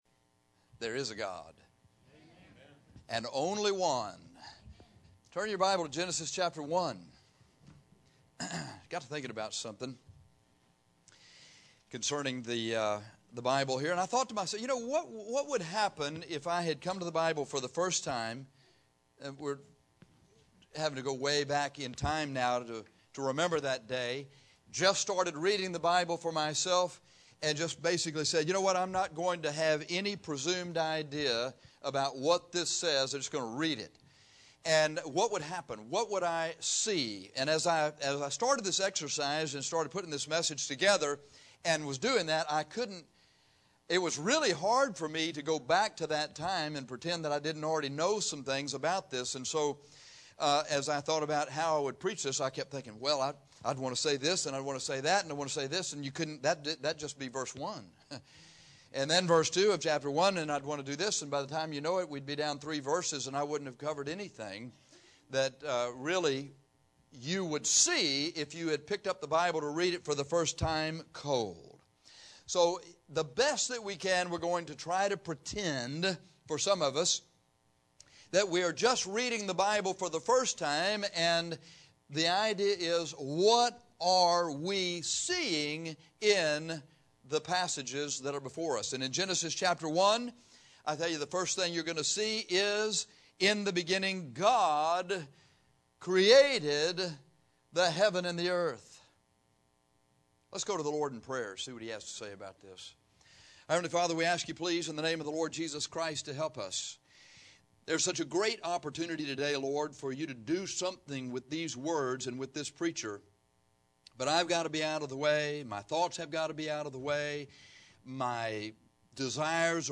This sermon is about what a man will learn about God in Genesis by just reading the first three chapters of the Bible for the first time.